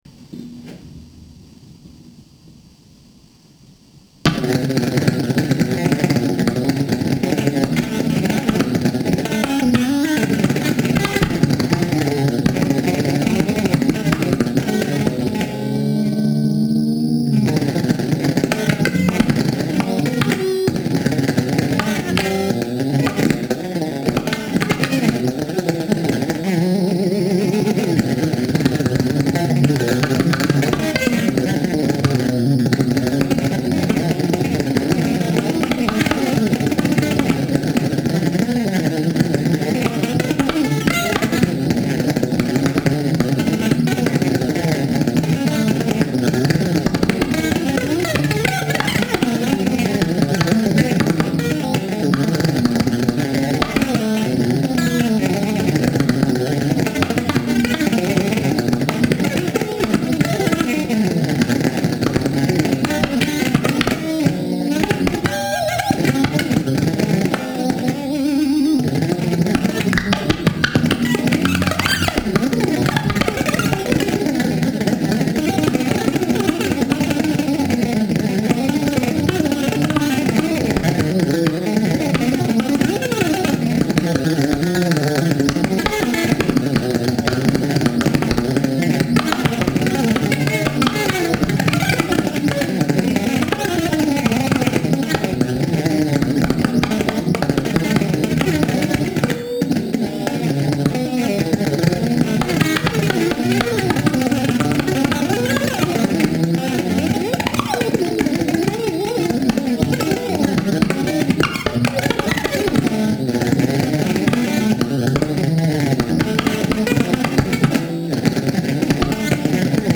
レコーディングはZT-AmpのLunchboxの爆音を、ステレオ・マイクで収音しておこなわれました。
エフェクターは特殊なファズと、コンプレッサー、それに僅かなリバーブのみです。
ギター愛好家の方々にはもちろん、現代音楽、先端的テクノ、実験音楽をお好きな方々にもお薦めのアルバムです。